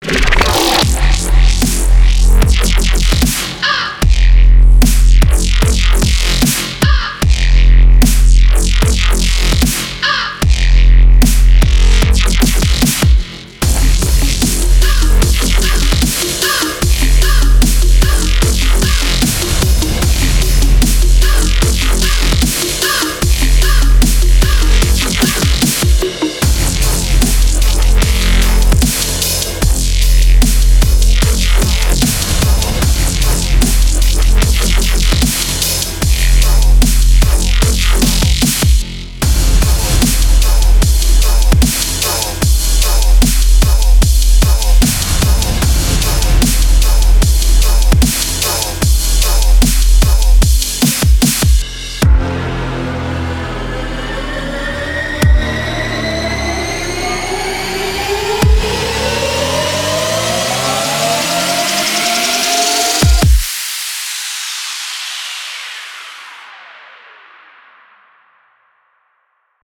• Качество: 256, Stereo
ритмичные
громкие
жесткие
мощные
EDM
без слов
басы
Четкий даб